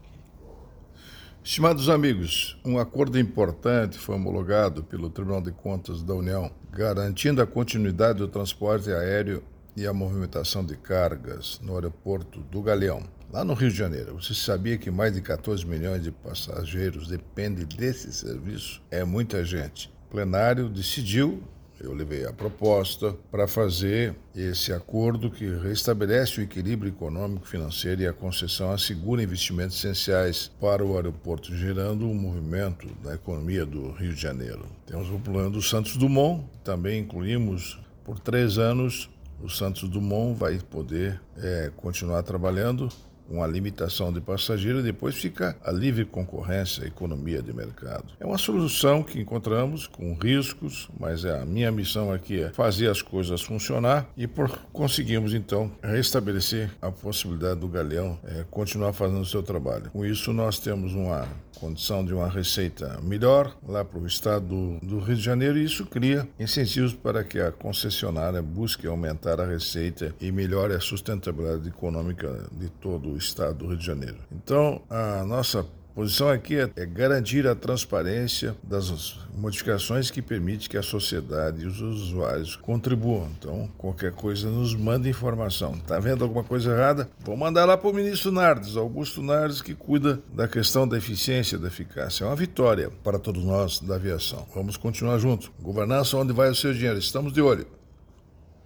Comentário de Augusto Nardes.